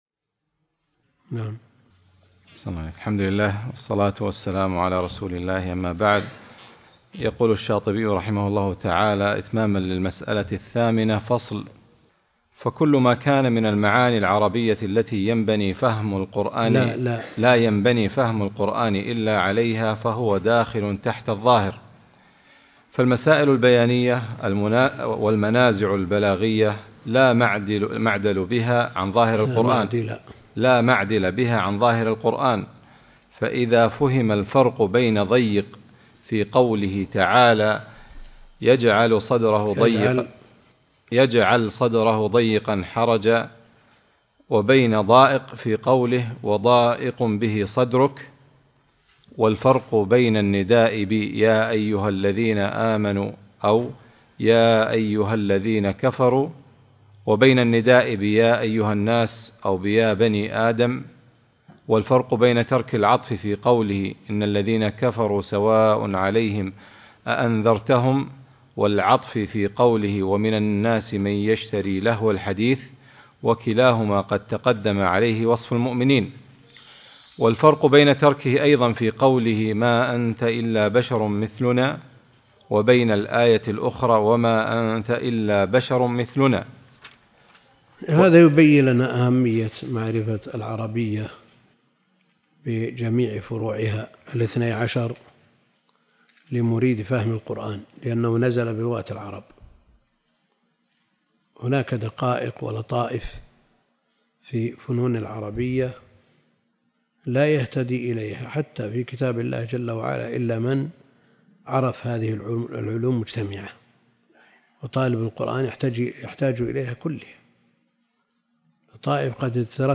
الدرس ( 123) التعليق على الموافقات